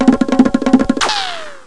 Sons de humor 47 sons
correr2.wav